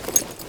tac_gear_16.ogg